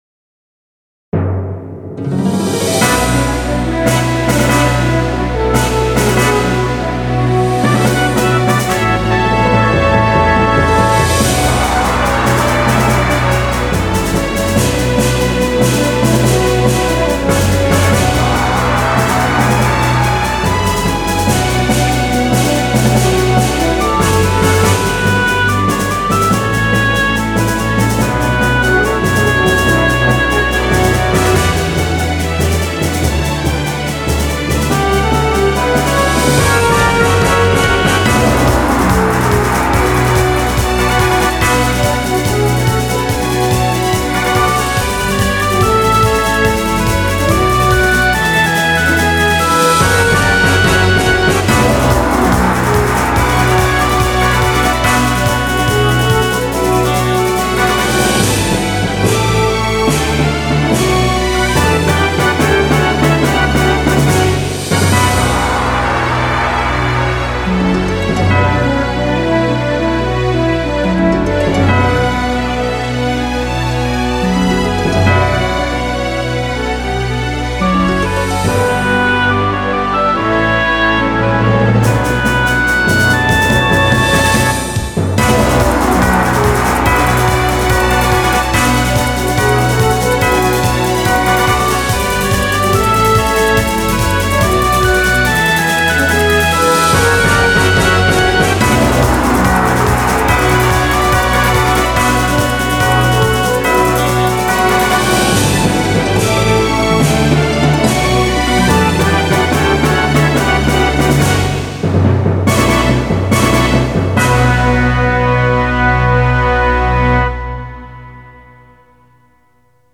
BPM140-143
Audio QualityPerfect (High Quality)
Genre: R-PLAY BATTLE.
Obviously based on a RPG boss battle song, haha.